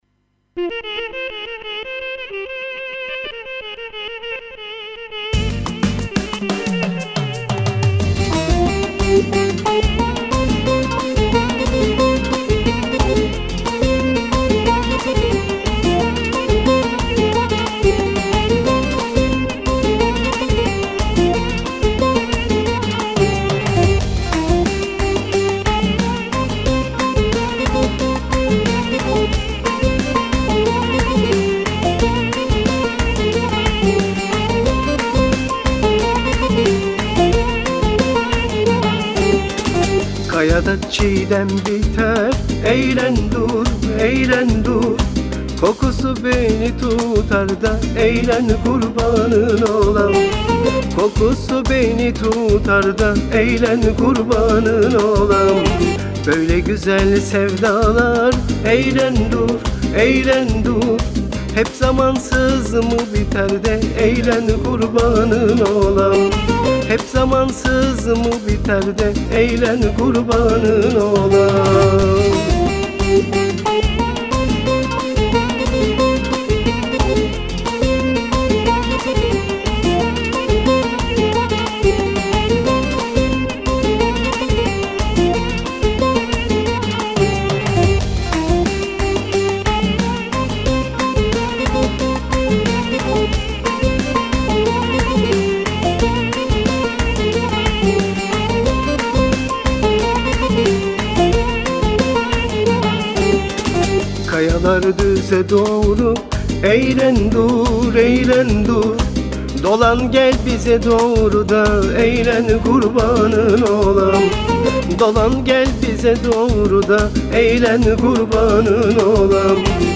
Eser Şekli : Halk Müziği
hareketli tarzda